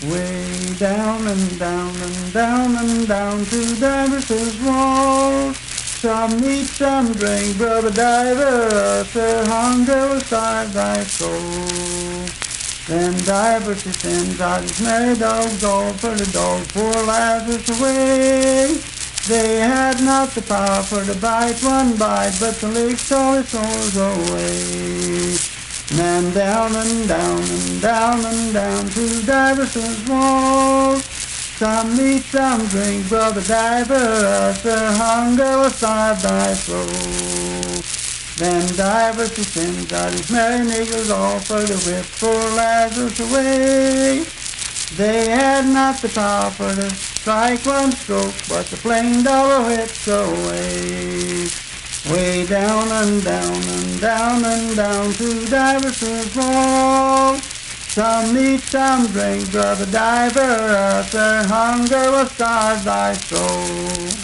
Unaccompanied vocal music
Verse-refrain 3(4)&R(4).
Performed in Dryfork, Randolph County, WV.
Hymns and Spiritual Music
Voice (sung)